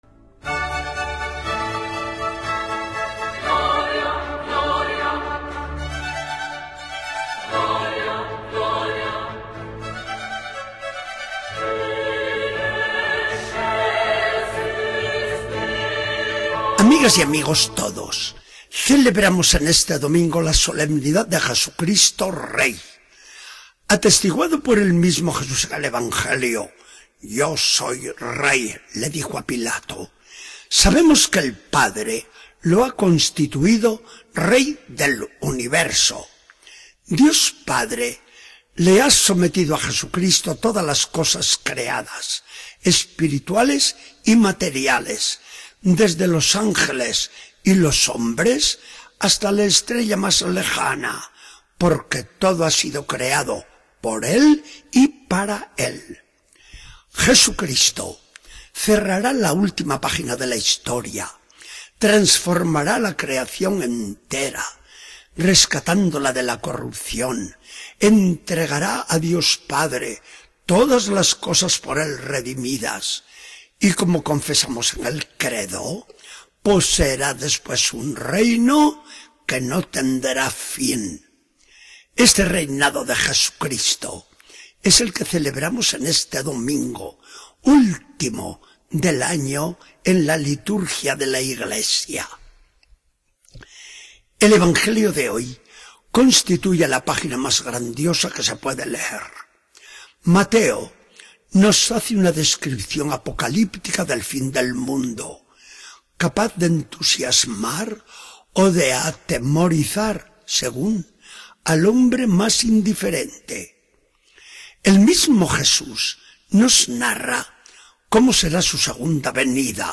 Charla del día 23 de noviembre de 2014. Del Evangelio según San Marcos 13, 33-37.